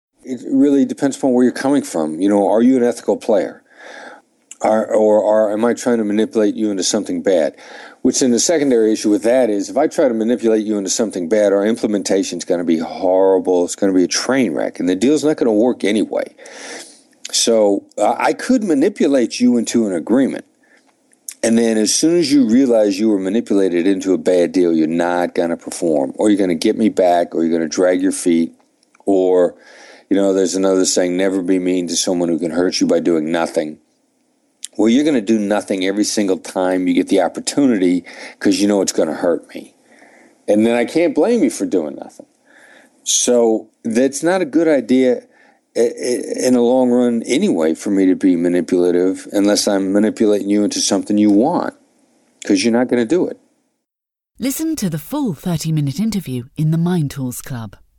Voss talks more about the ethics of negotiation in this audio clip, from our Expert Interview podcast .